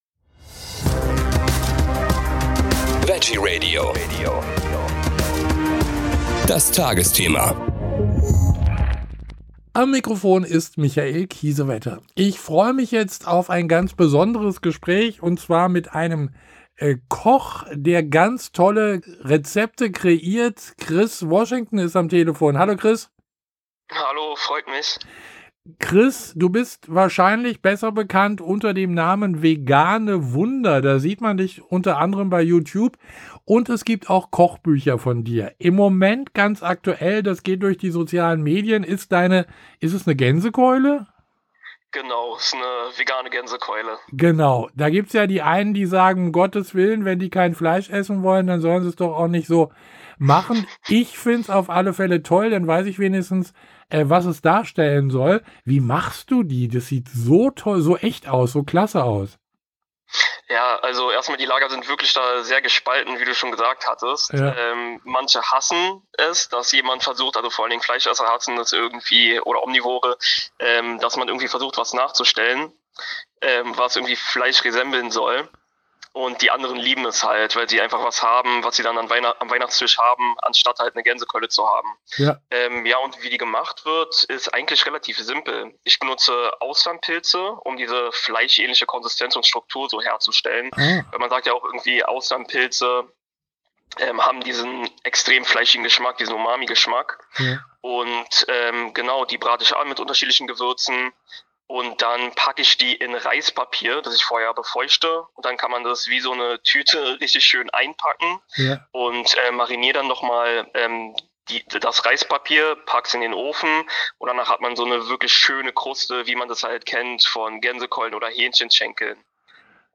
Sein größtes Ziel ist es Menschen zu zeigen wie vielfältig eine vegane Ernährung sein kann und man geschmacklich auf nichts verzichten muss. Wir haben mit ihm gesprochen.
Gespräch